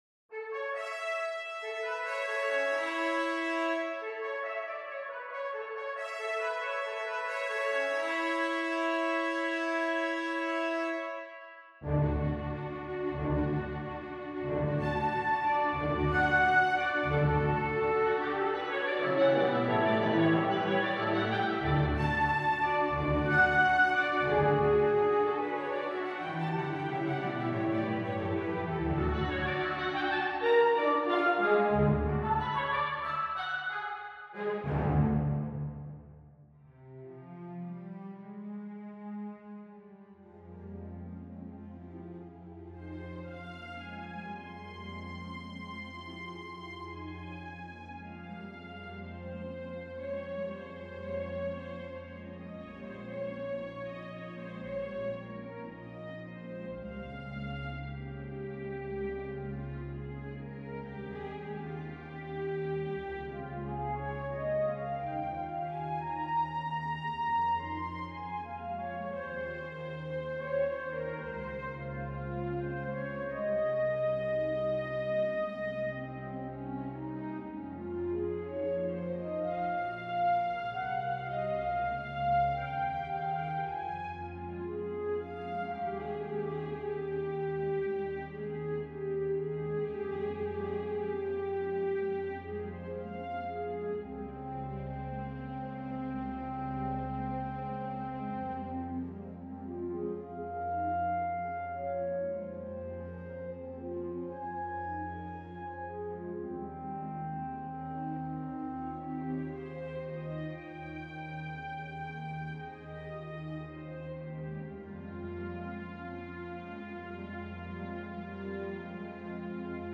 Orchestra: picc 2222; 4331; timp; strings
Heralds of Spring for orchestra 2016 6'30" Orchestra: picc 2222; 4331; timp; strings (The following audio demo was made using instruments from the Vienna Symphonic Library.)